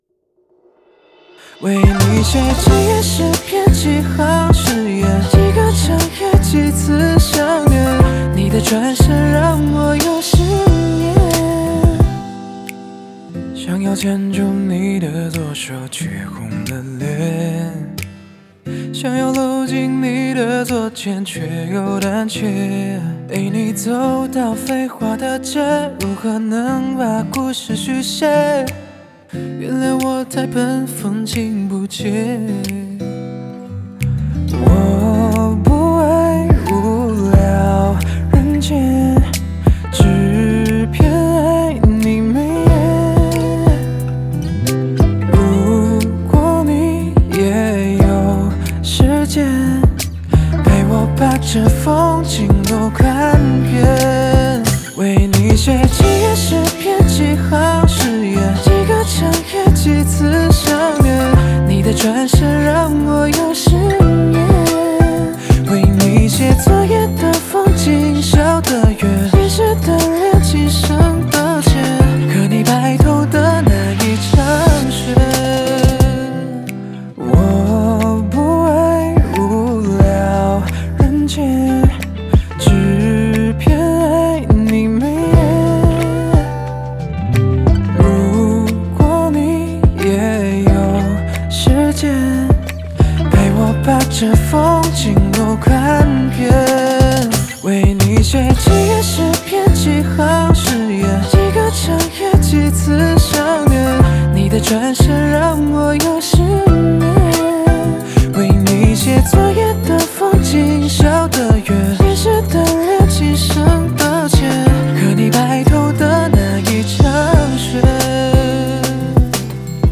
和声
吉他